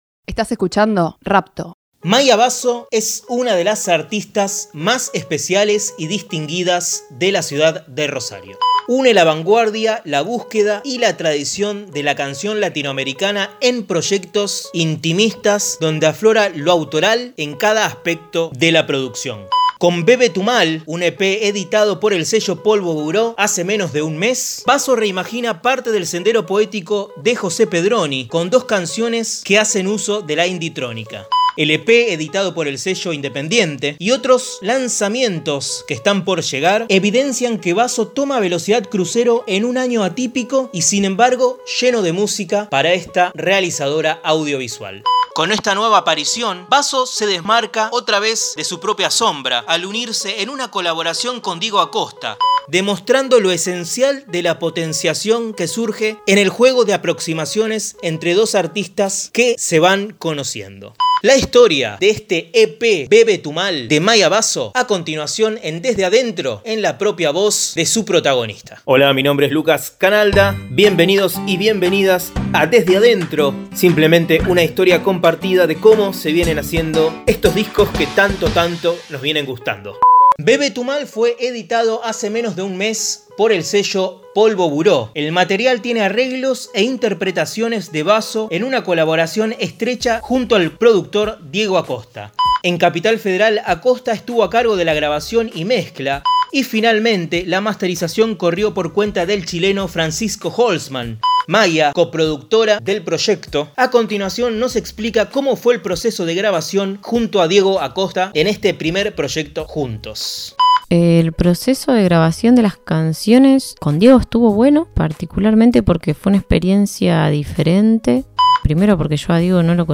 Desde adentro es un podcast que busca revelar las instancias de producción de las últimas novedades de la música independiente. Los discos más recientes desde la voz de sus protagonistas.
La canción que cierra el podcast es “Mamá angustia”.